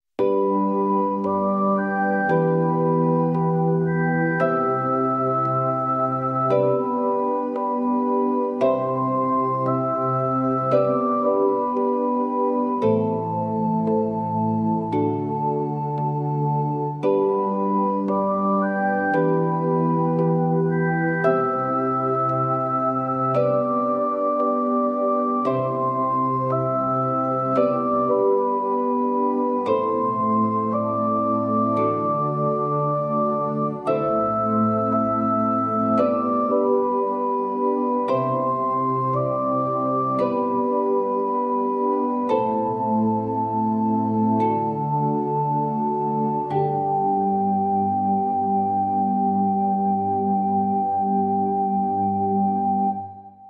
Deep Sleep